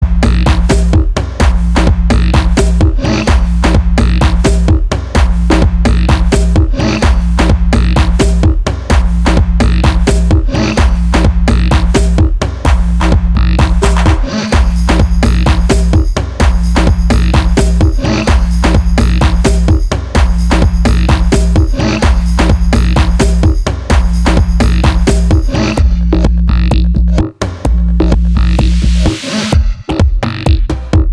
Клубные [95]